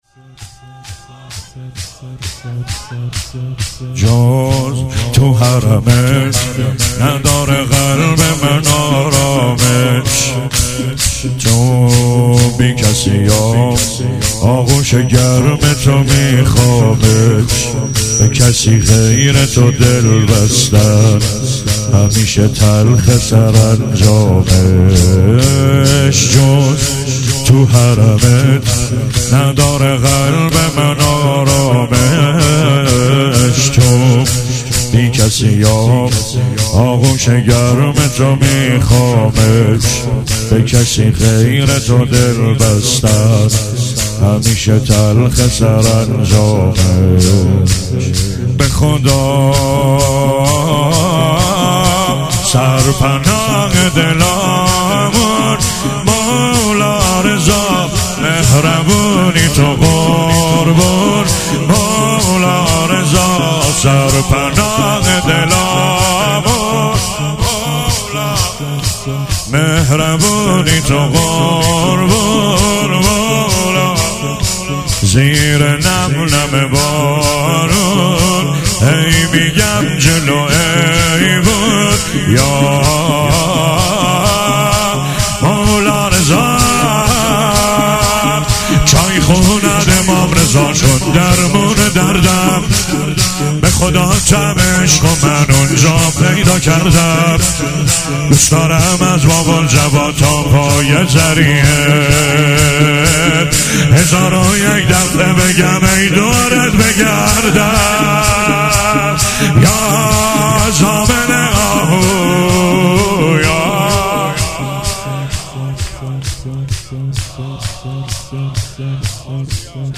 در مجلس هئیت علمدار مشهد الرضا (ع)
مداحی به سبک شور اجرا شده است.